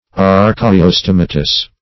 Meaning of archaeostomatous. archaeostomatous synonyms, pronunciation, spelling and more from Free Dictionary.
Search Result for " archaeostomatous" : The Collaborative International Dictionary of English v.0.48: Archaeostomatous \Ar`ch[ae]*o*stom"a*tous\, a. [Gr.